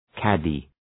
Προφορά
{‘kædı}